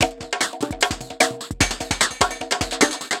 Percussion 19.wav